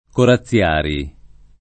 Corazziari [ kora ZZL# ri ] cogn.